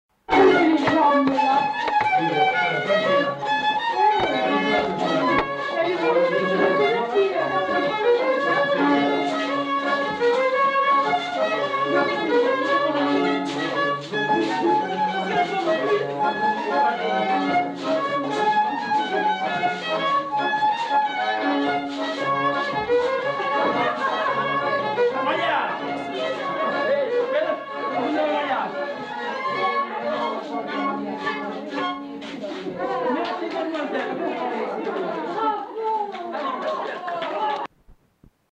Lieu : Allons
Genre : morceau instrumental
Instrument de musique : violon ; accordéon diatonique
Danse : rondeau